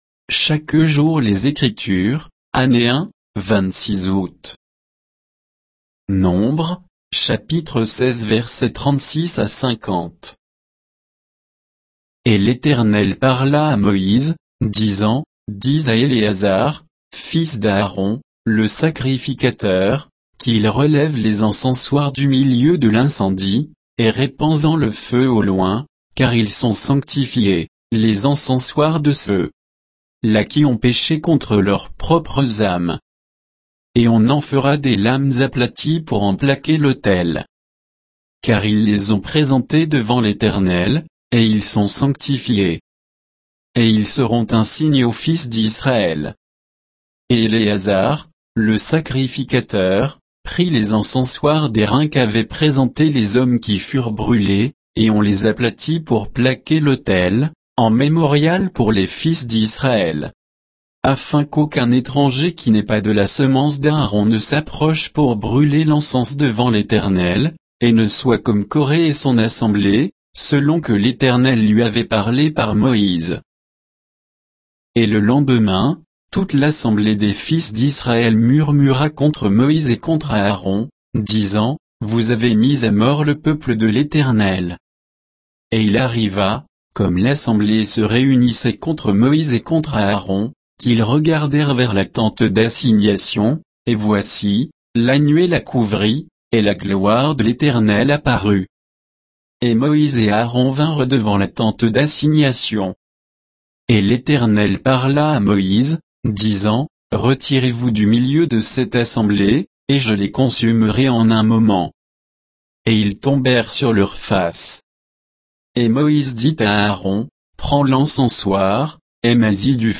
Méditation quoditienne de Chaque jour les Écritures sur Nombres 16